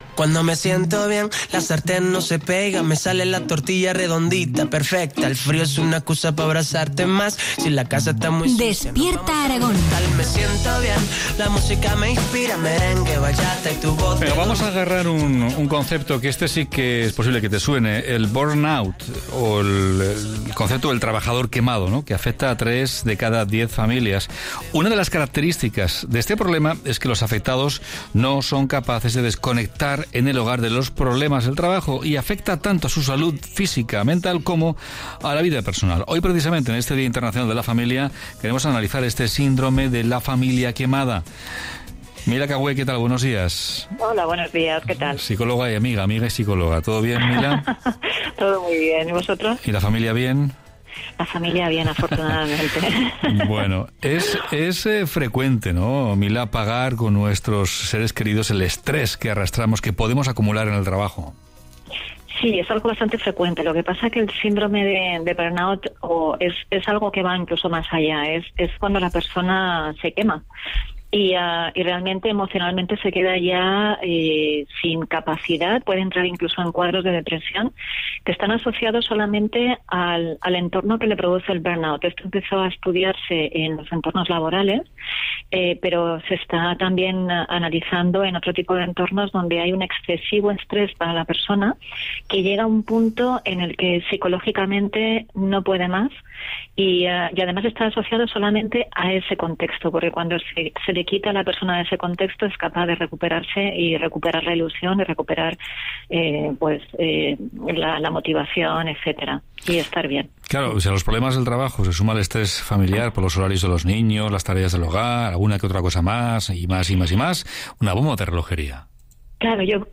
Espero que os guste, aunque algunas interferencias en la conexión (por mi parte) hicieron un poco atropellada la intervención.